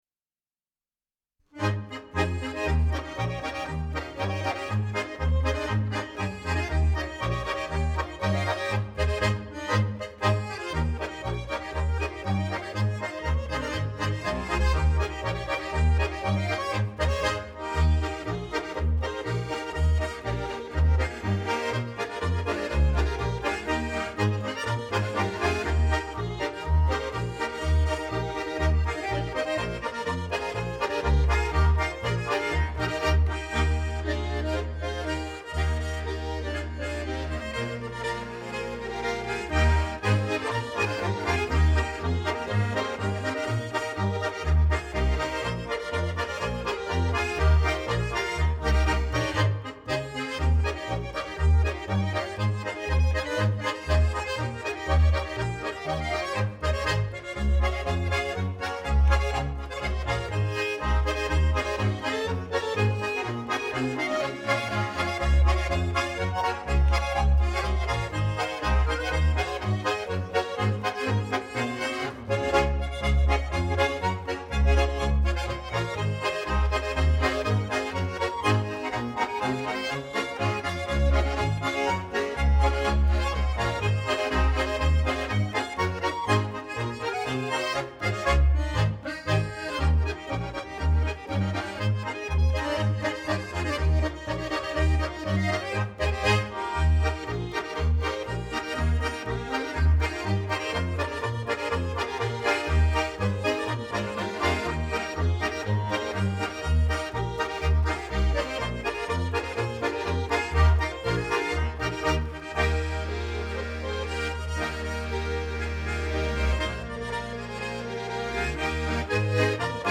Klarinette